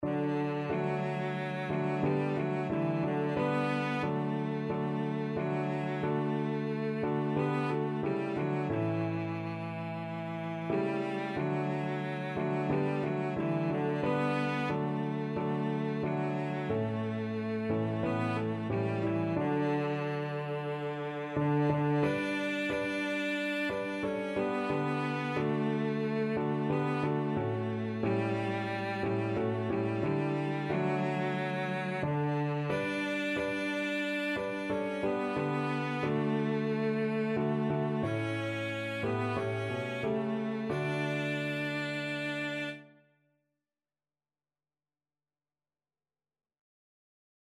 Christian Christian Cello Sheet Music I Know Whom I Have Believed
Cello
D major (Sounding Pitch) (View more D major Music for Cello )
4/4 (View more 4/4 Music)
Classical (View more Classical Cello Music)